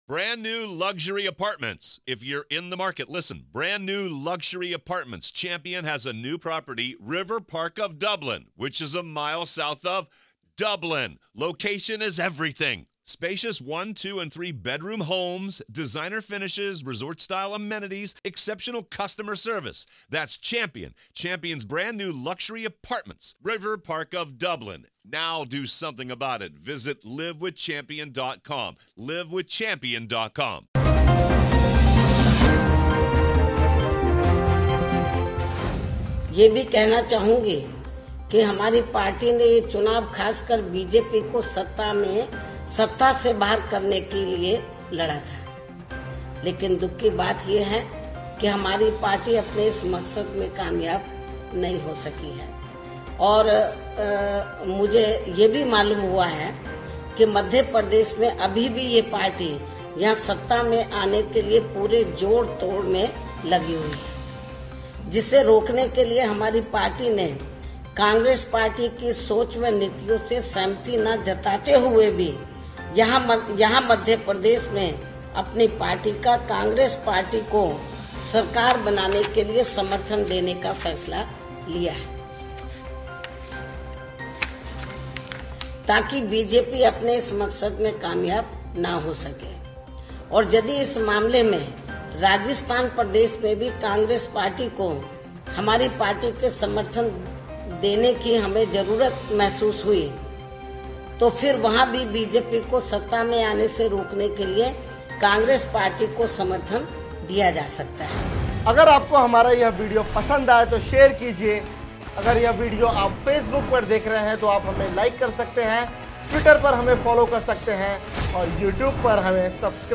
न्यूज़ रिपोर्ट - News Report Hindi / मध्य प्रदेश में मायावती कांग्रेस को समर्थन देने का किया ऐलान